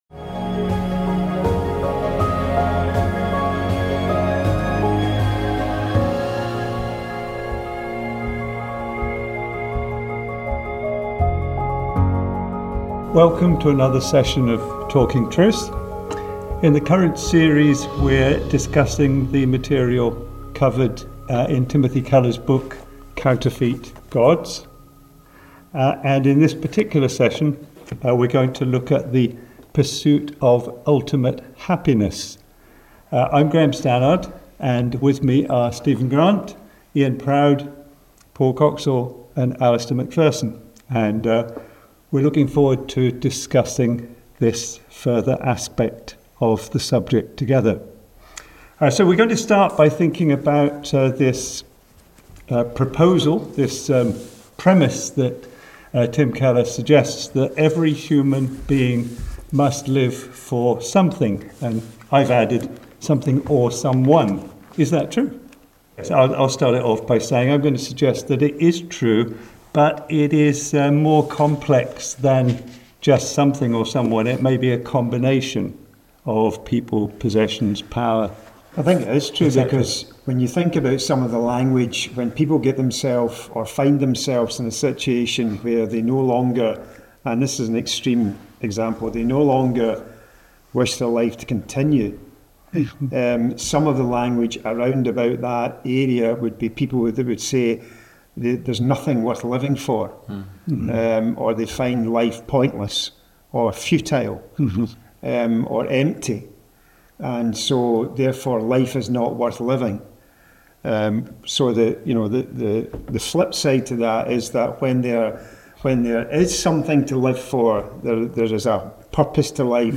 round-table discussion